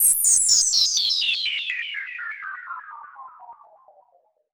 fxpTTE06020sweep.wav